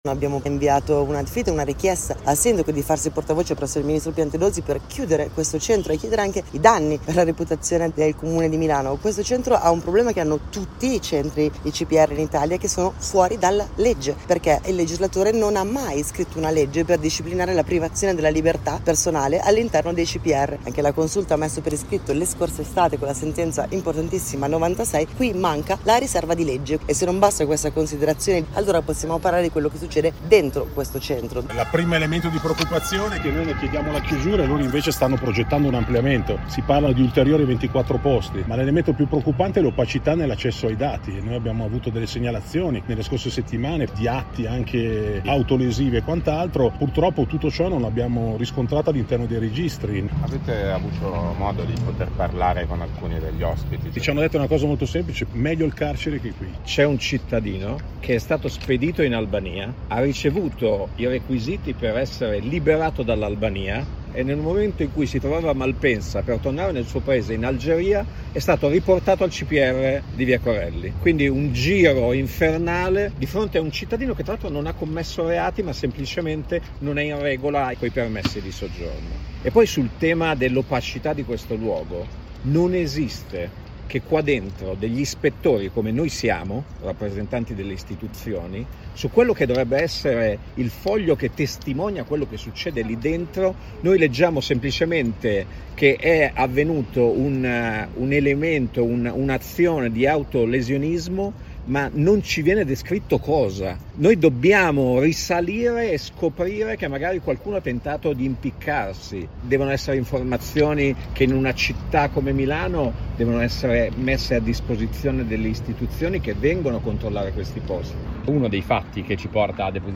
Sentiamo le voci dei promotori della diffida che parlano anche delle condizioni di chi  si trova all'interno del centro per i rimpatri.